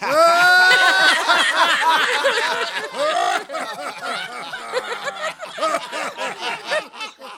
Laughing.wav